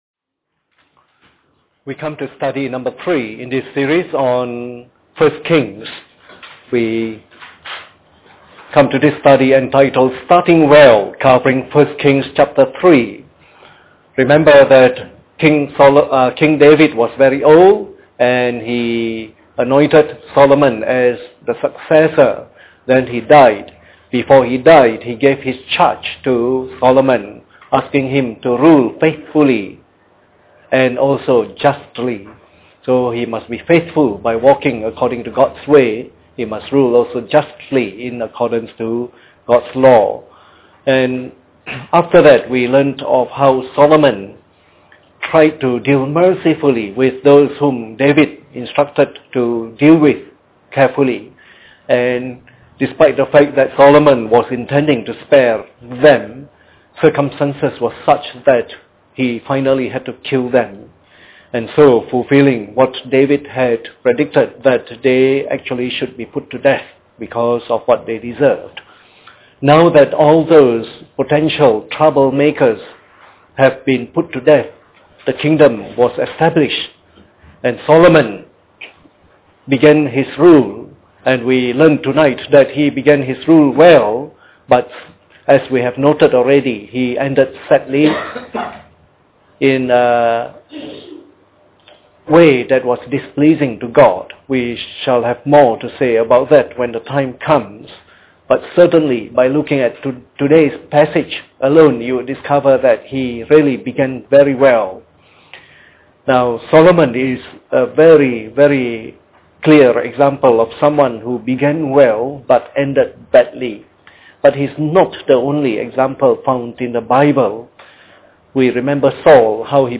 Part of the “1 Kings” message series delivered during the Bible Study sessions.